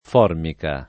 vai all'elenco alfabetico delle voci ingrandisci il carattere 100% rimpicciolisci il carattere stampa invia tramite posta elettronica codividi su Facebook formica [ f 0 rmika ; ingl. foom # ikë ] s. f. («laminato plastico») — nome depositato